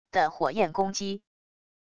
的火焰攻击wav音频